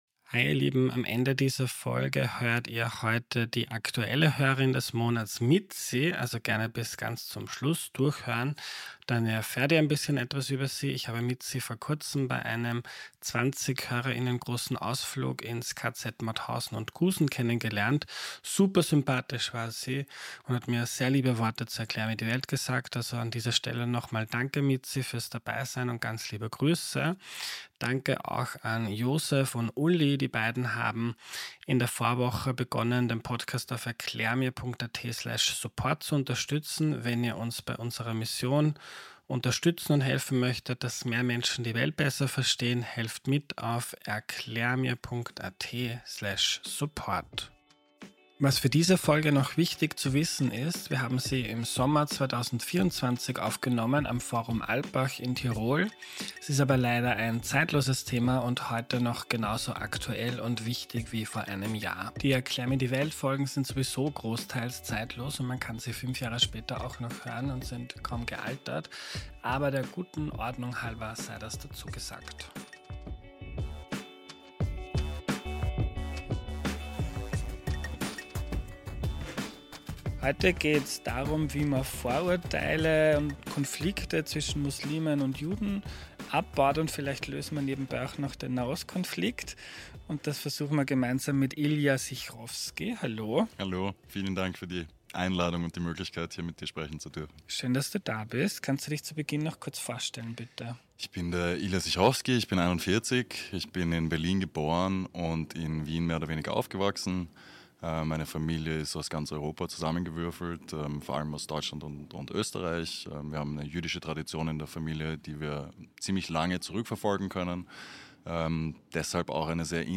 Ein Gespräch über das Bauen von Brücken zwischen Gruppen, die sich manchmal verfeindet gegenüberstehen.